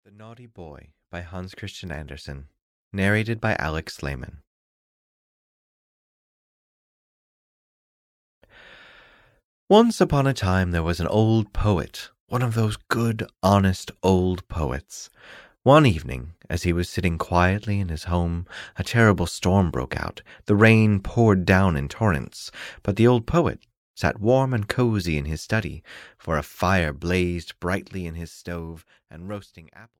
The Naughty Boy (EN) audiokniha
Ukázka z knihy